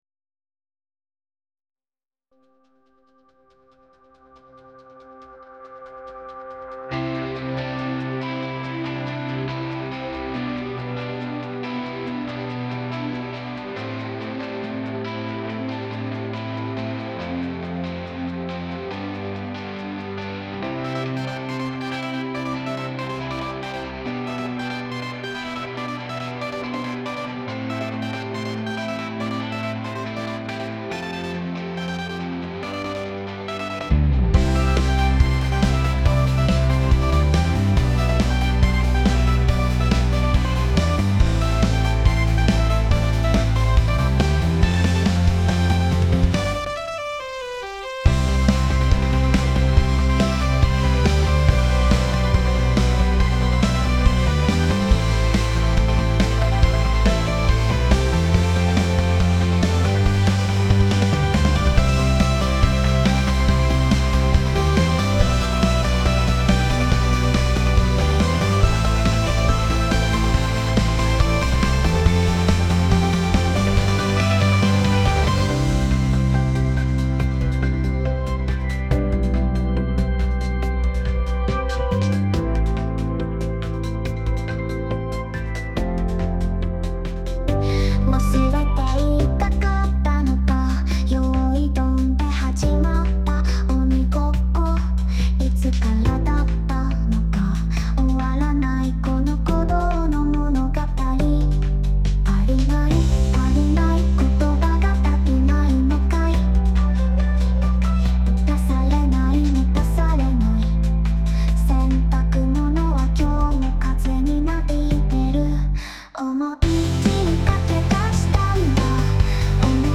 歌あり 疾走感